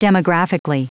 Prononciation du mot demographically en anglais (fichier audio)
Prononciation du mot : demographically